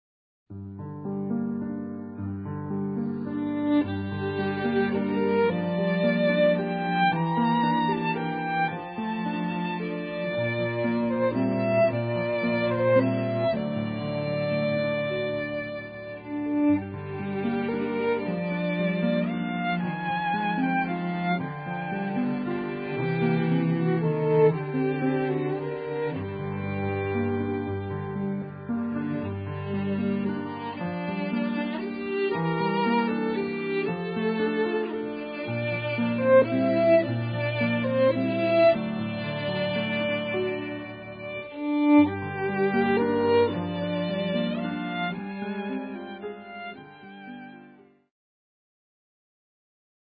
Cello
Grand Piano